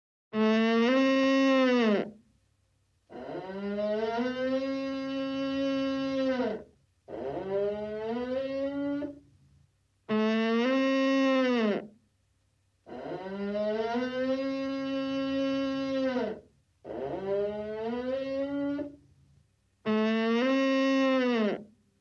Звуки лося
Голос могучего зверя из дикой природы